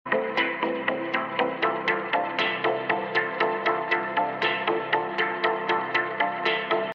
5 seconds timer green screen sound effects free download